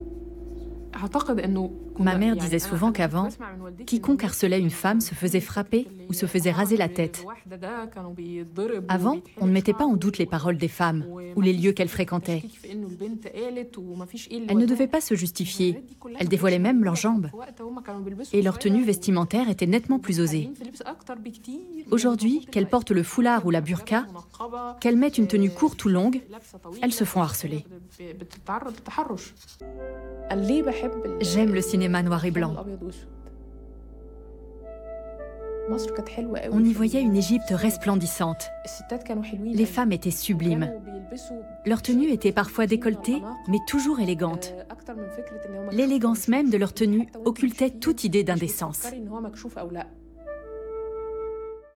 Voice Over Arte voix sérieuse voix douce voix naturelle Voice Over Catégories / Types de Voix Extrait : Votre navigateur ne gère pas l'élément video .